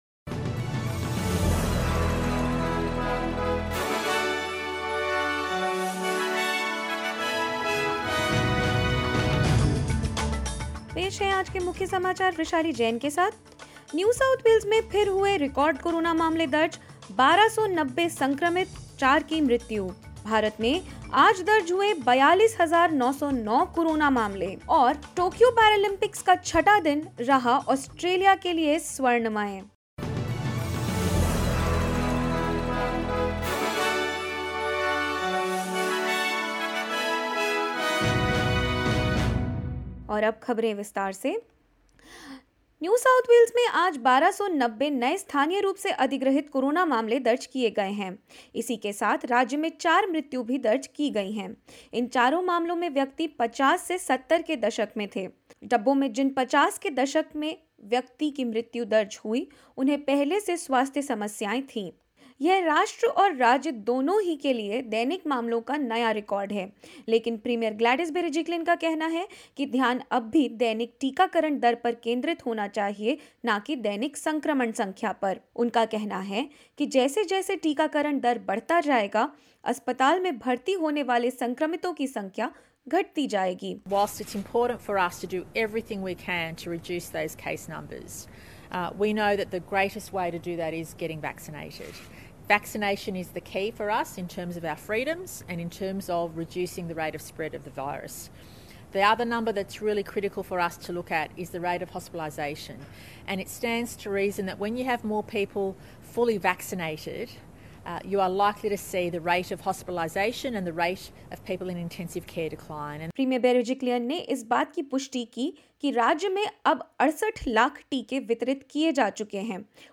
In this latest SBS Hindi News bulletin of Australia and India: New South Wales registers yet another national high of infections with 1290 cases and four deaths; Victoria records 73 new locally acquired cases and administers more than 26,000 vaccines in last 24 hours and more.